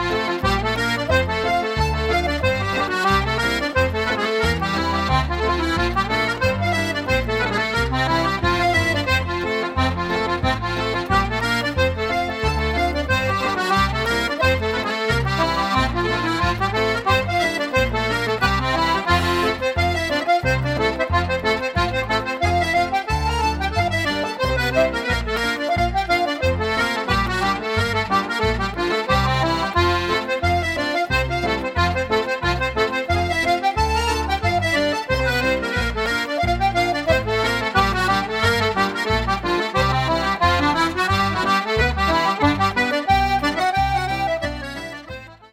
Accordion
With guest musicians
Piano
Harp
Fiddle
Irish traditional music
Hornpipes: [4:23]